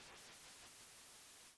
spin.wav